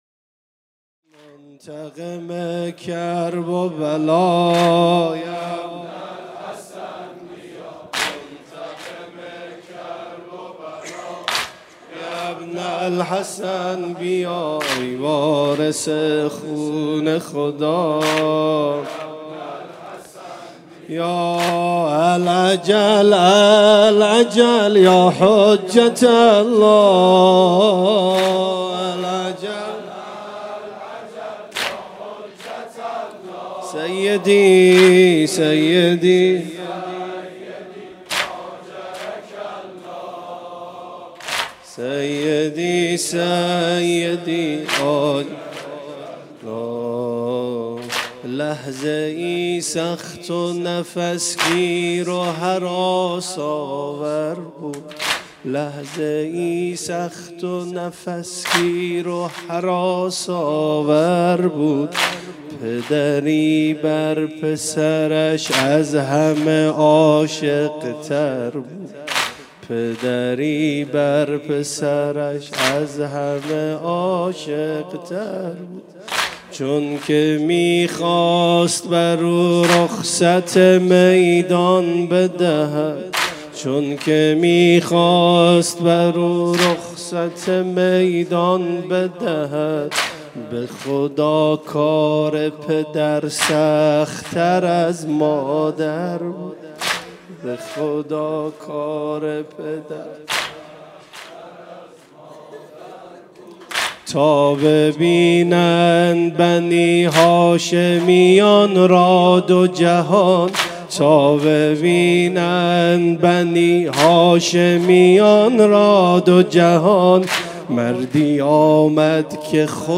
شب تاسوعا محرم 97 - هیئت شبان القاسم - العجل العجل یا حجة الله